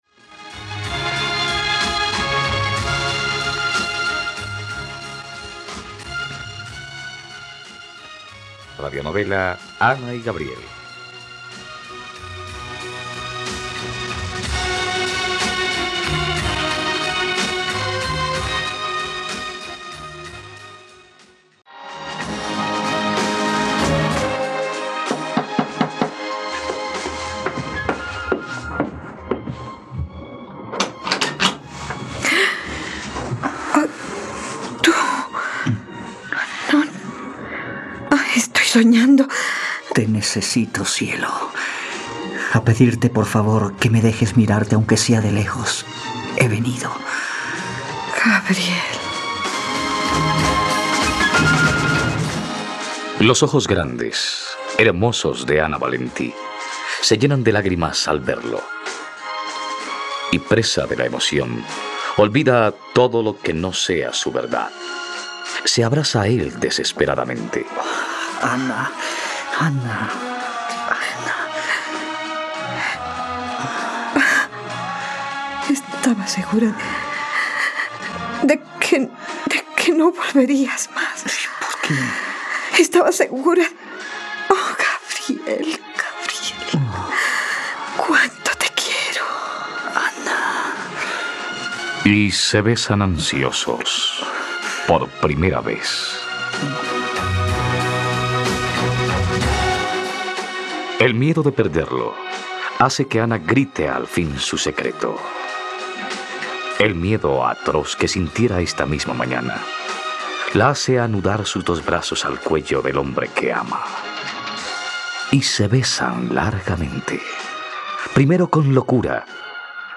Ana y Gabriel - Radionovela, capítulo 50 | RTVCPlay